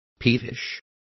Complete with pronunciation of the translation of peevish.